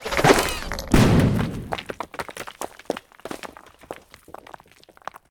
grenade.ogg